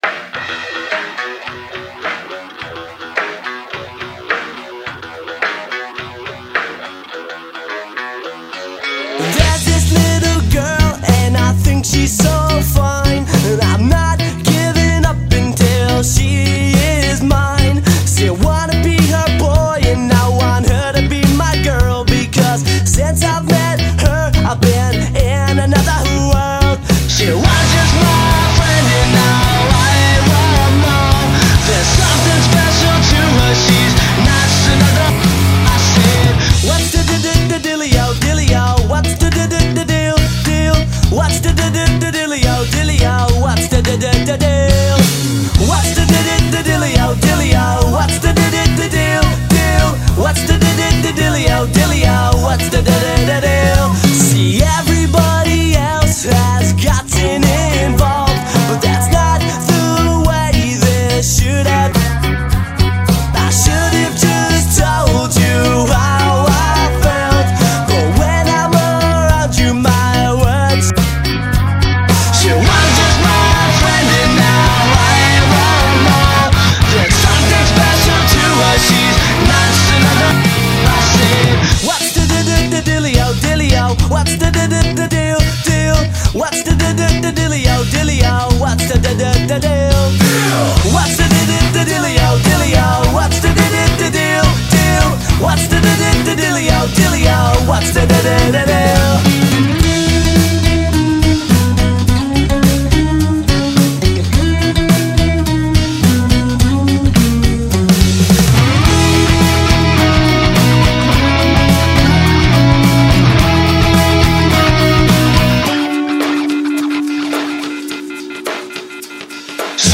BPM105
Audio QualityPerfect (High Quality)
rock